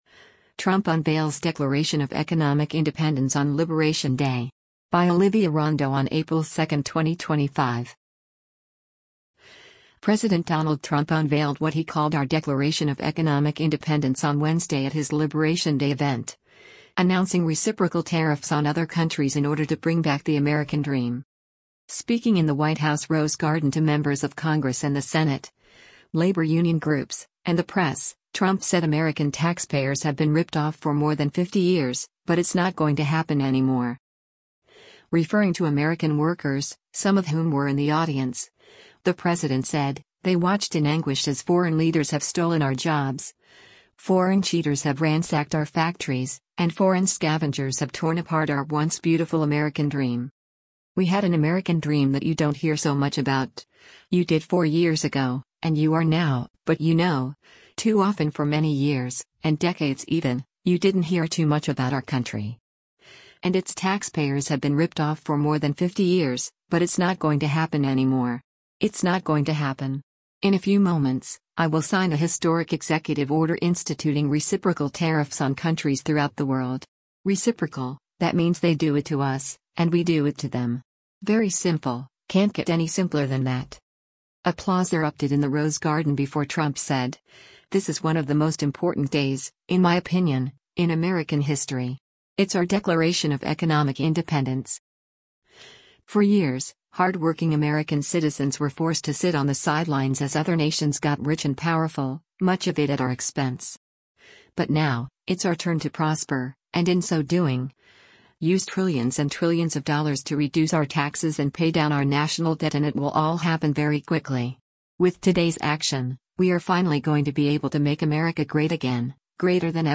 Speaking in the White House Rose Garden to members of Congress and the Senate, labor union groups, and the press, Trump said American taxpayers have been “ripped off for more than 50 years, but it’s not going to happen anymore”:
Applause erupted in the Rose Garden before Trump said, “This is one of the most important days, in my opinion, in American history. It’s our Declaration of Economic Independence.”